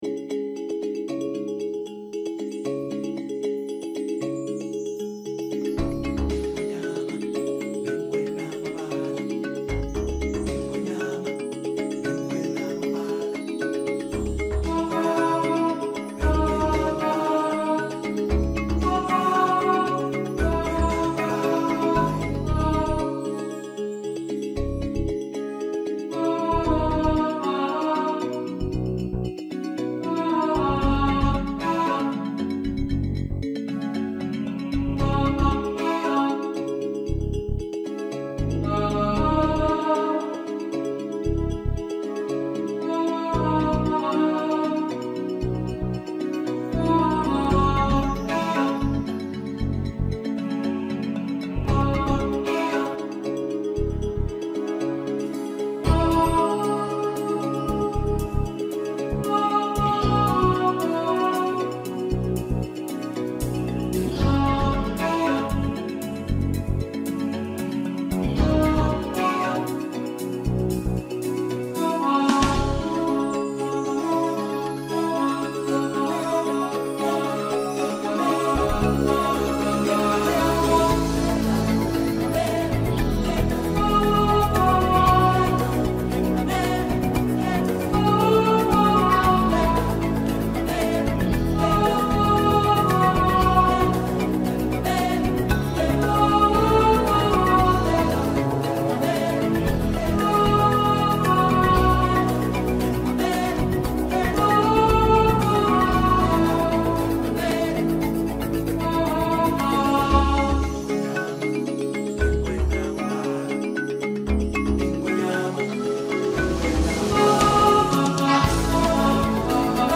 He Lives In You – Alto | Ipswich Hospital Community Choir
He-Lives-In-You-Alto.mp3